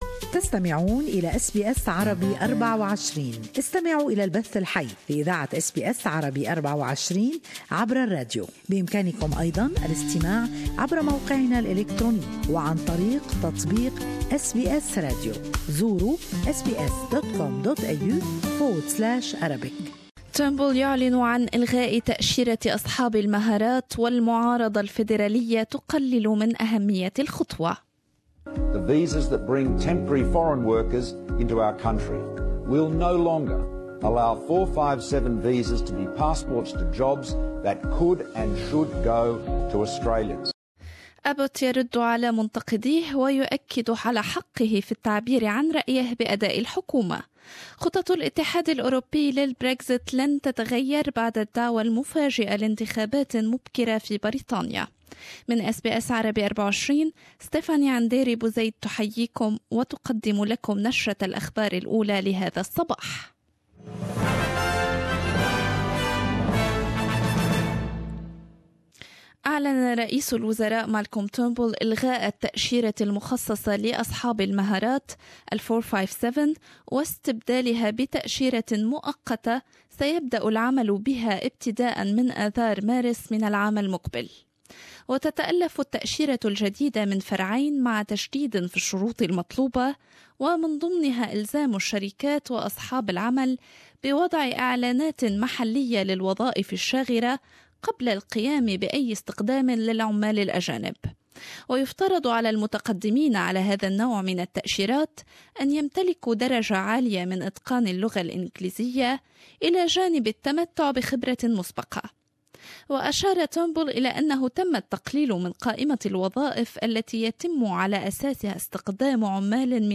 In this bulletin ... * Britain to head to the polls as Theresa May calls a snap election * Mixed reaction to government's decision to scrap the 457 skilled migrant visa And * Donald Trump's deputy insists the U-S wants diplomacy to solve the North Korean nuclear issue